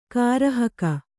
♪ kārahaka